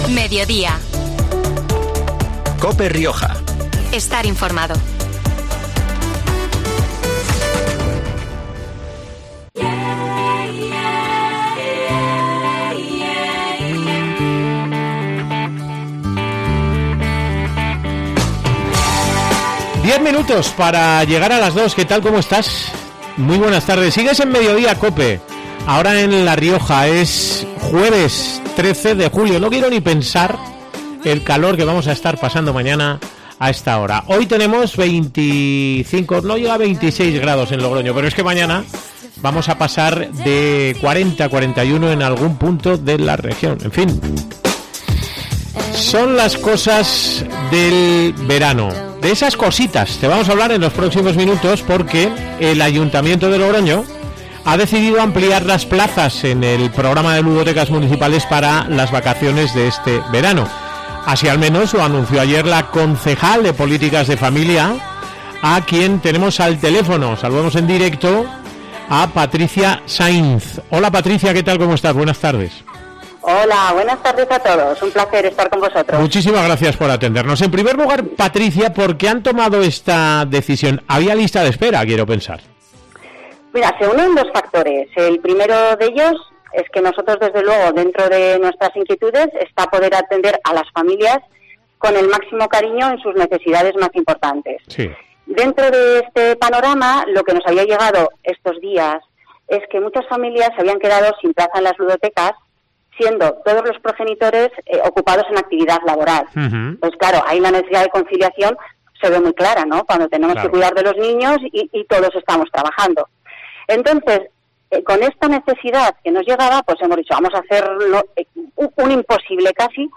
Así lo ha confirmado en COPE Rioja la concejal de Políticas de Familia, Patricia Sainz, quien ha explicado que esta decisión se toma “para dar respuesta a las necesidades de conciliación de las familias logroñesas” a través de un programa de gran calidad y que resulta enormemente demandado.